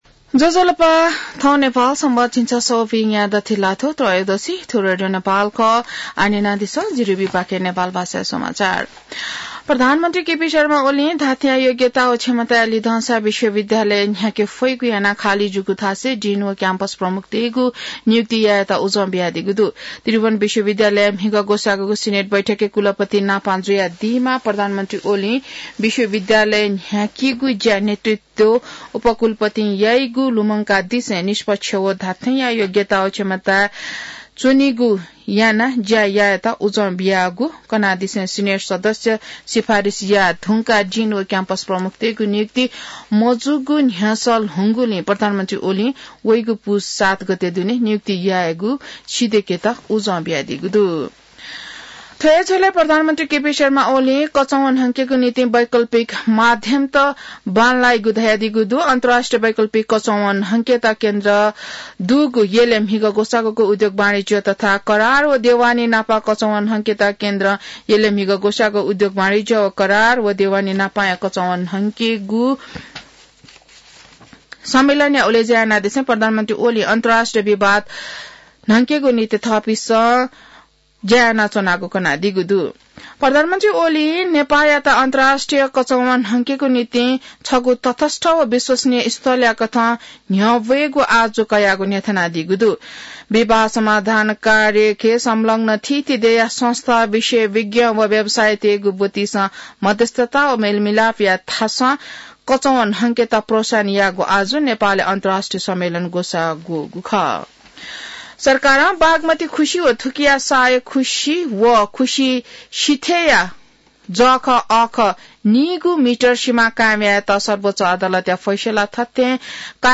नेपाल भाषामा समाचार : २९ मंसिर , २०८१